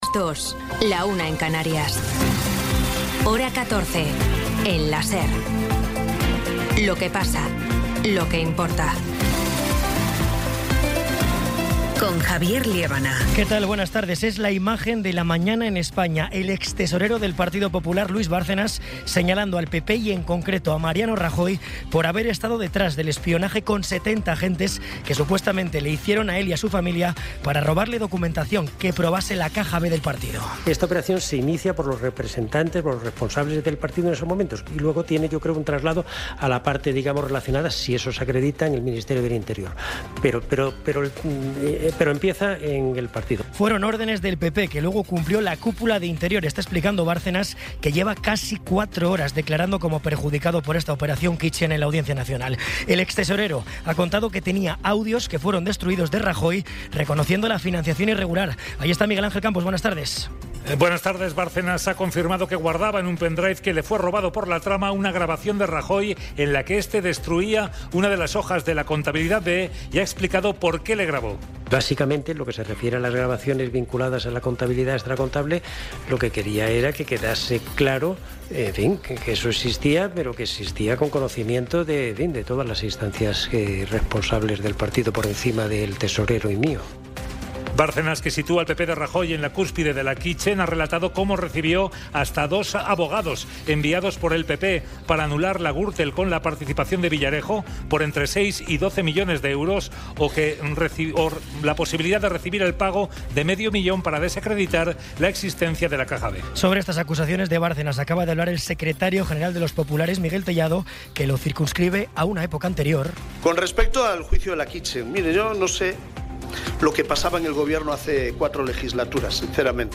Resumen informativo con las noticias más destacadas del 20 de abril de 2026 a las dos de la tarde.